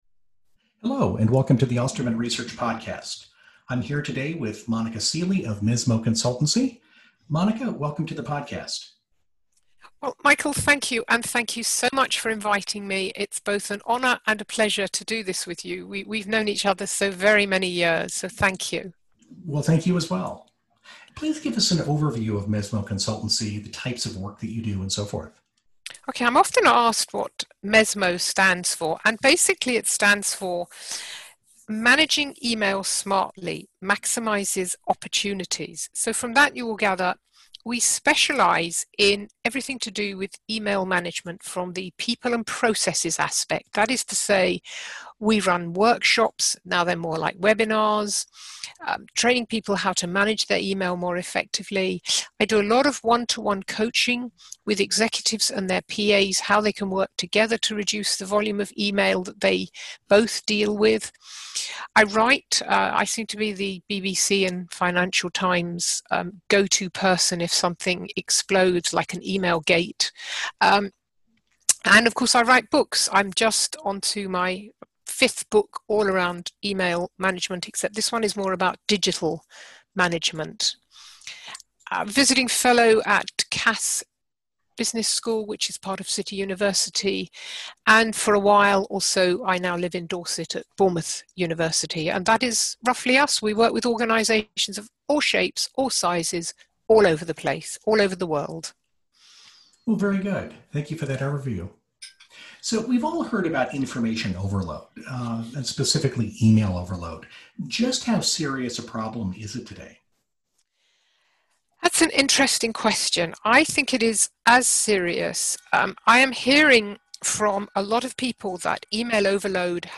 A Conversation About Email and Information Overload